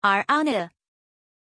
Pronunciation of Orania
pronunciation-orania-zh.mp3